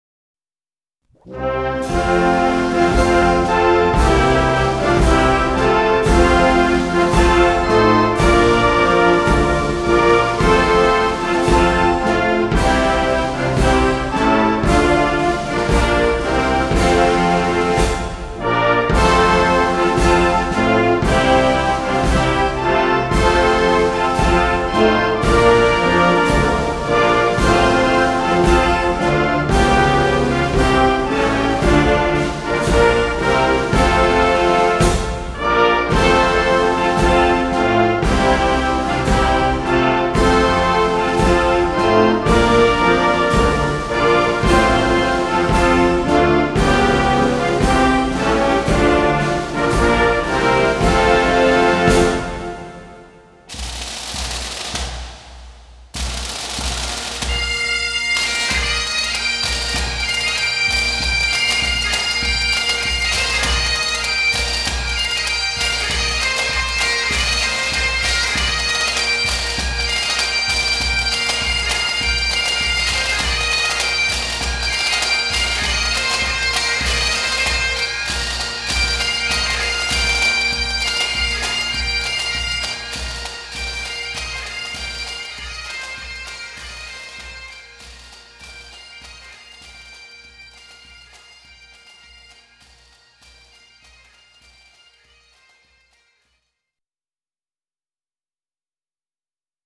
苏格兰风笛（Great Highland Bagpipe）是乐器的一种，属使用哨片的气鸣乐器。
音管拥有开放式端口，所以在吹奏时音乐是很难停下来的，这意味着大多数的风笛在吹奏过程中声音始终是连贯的。